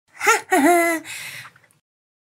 hahaha.mp3